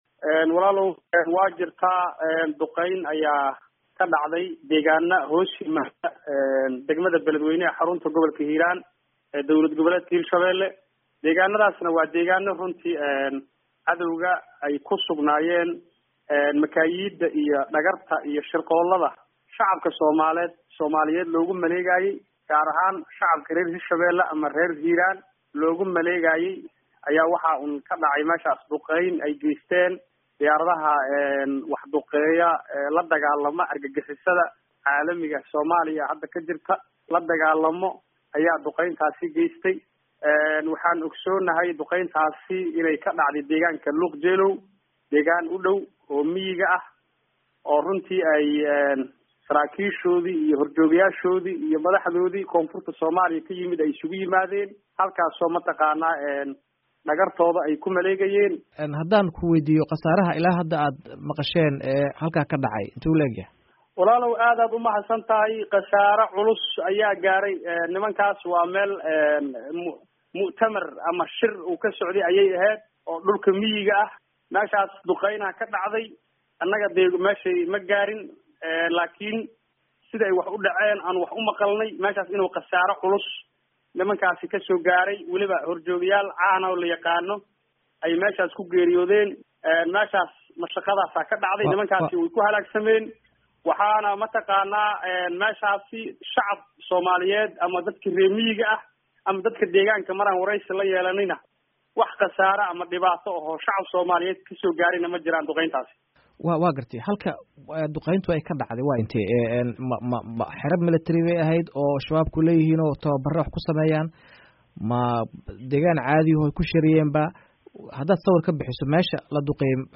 Gudoomiye ku xigeenka dhinaca Amniga ee maamulka Hiiraan Cabdiraxiin Muxumed Taakooy ayaa duqeyntan uga waramay